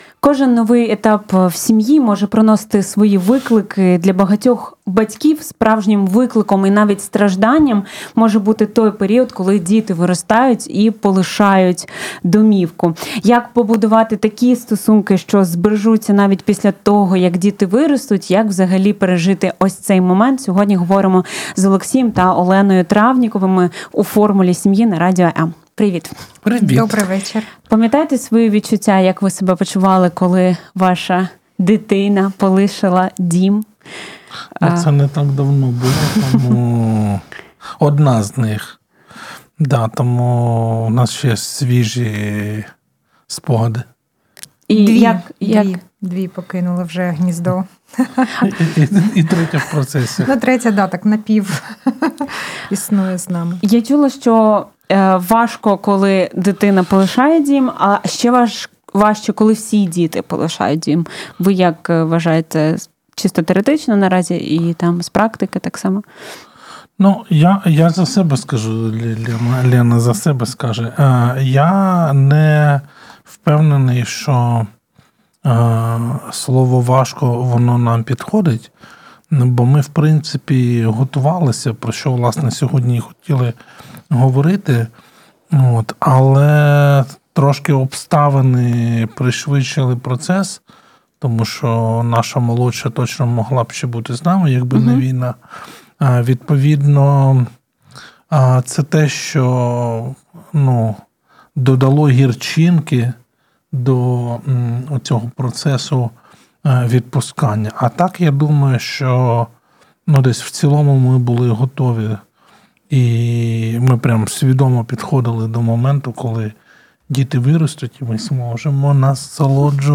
Завантажати запис ефіру на тему: Коли виростають діти